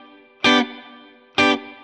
DD_StratChop_130-Gmaj.wav